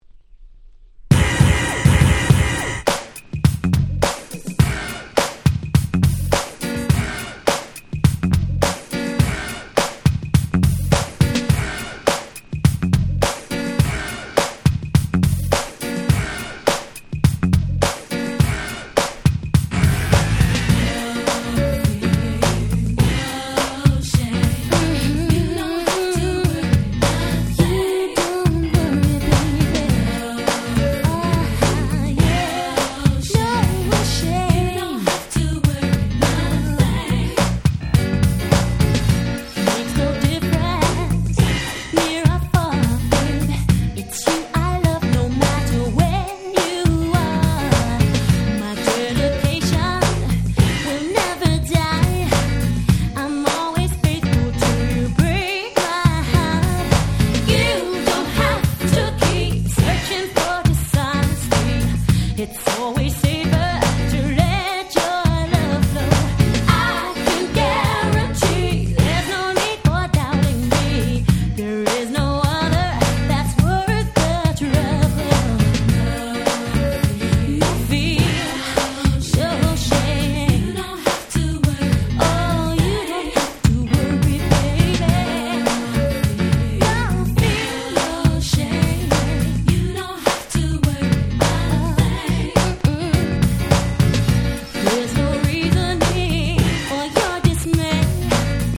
90' Nice R&B !!
タイプのキャッチーな1曲。